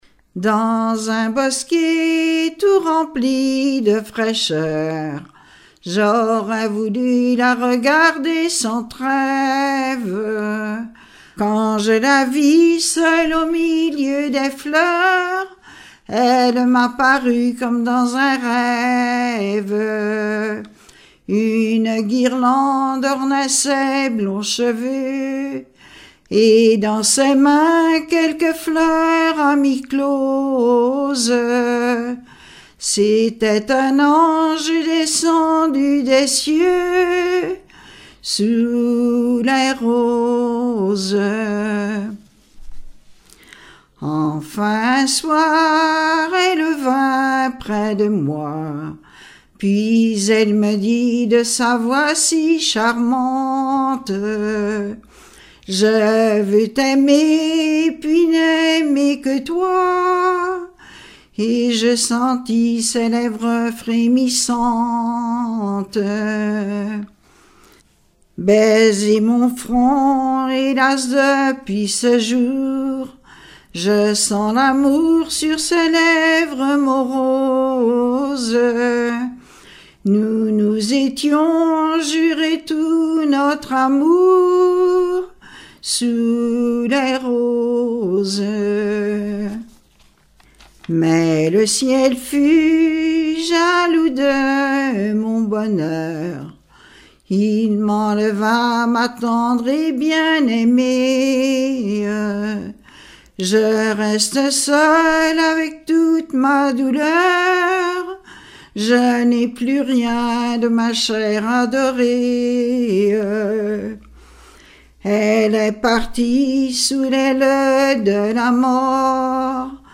sur un timbre
Répertoire de chansons populaires et traditionnelles
Pièce musicale inédite